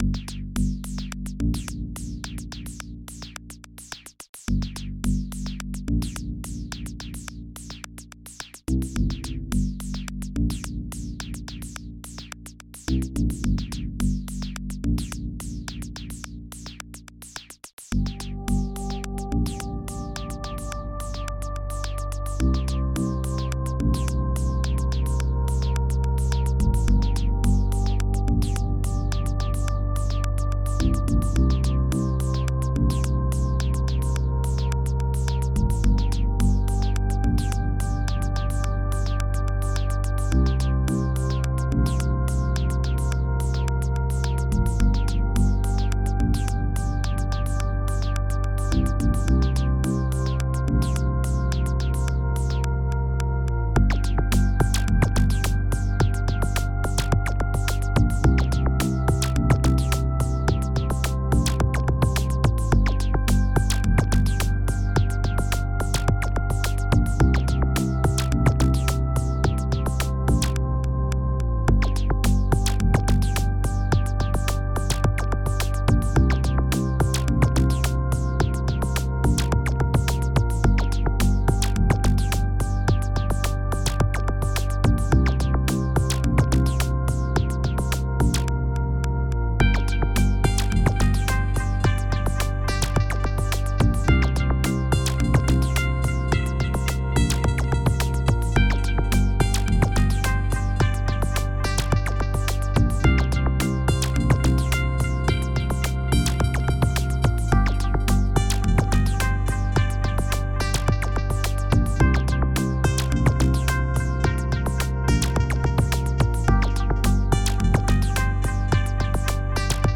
xm (FastTracker 2 v1.04)
for qdpb 64k intro
roland s-100